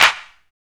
PRC METAL 0A.wav